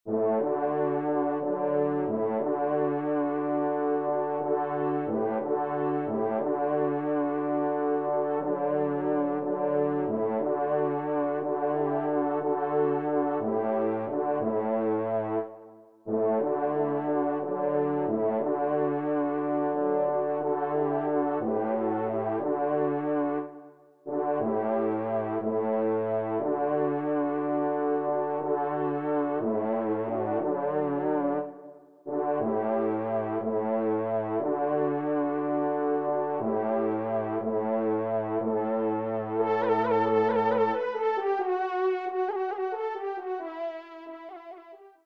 Pupitre 4° trompe (en exergue)